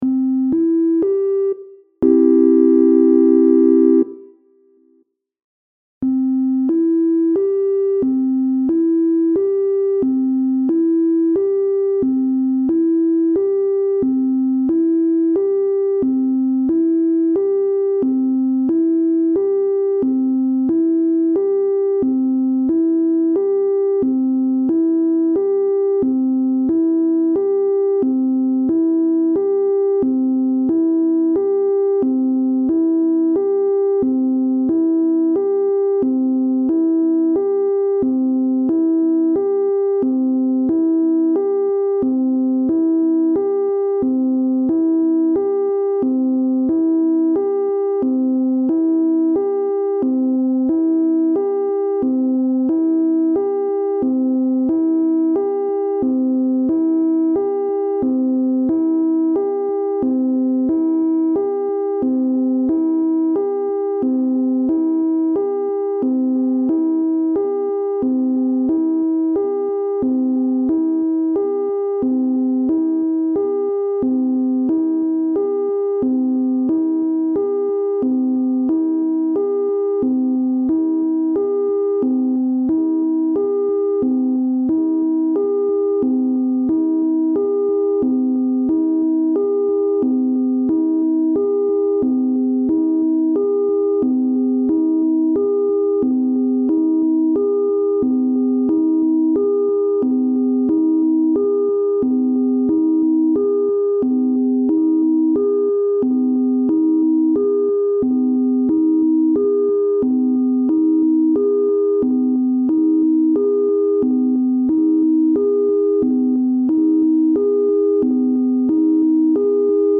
Tags: Modular Synth, experimental, noise
There are three pitches that start out as a C Major triad.
Recorded on Kihei, HI on 7/19/19
BPM: 120